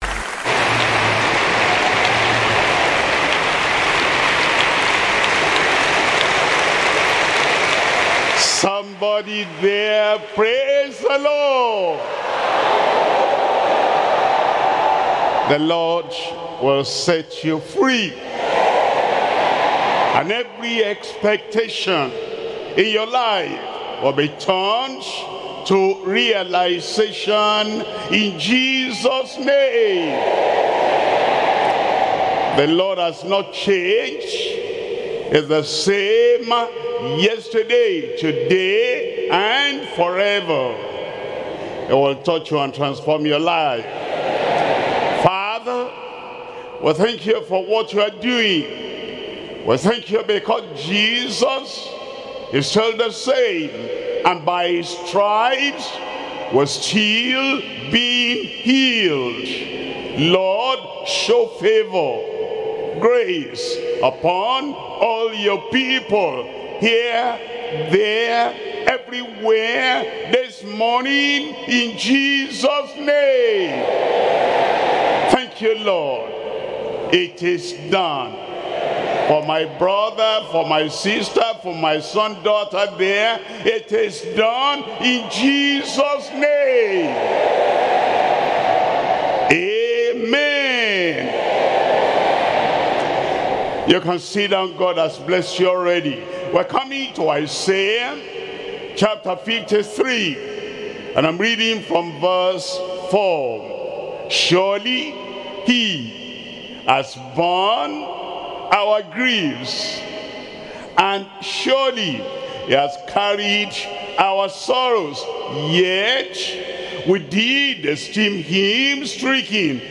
Sermons - Deeper Christian Life Ministry
2026 Global Easter Retreat